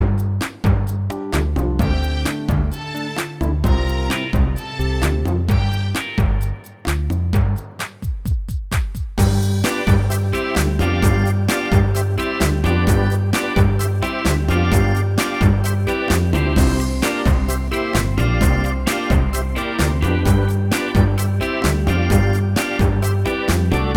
No Lead Guitar Pop (2000s) 3:42 Buy £1.50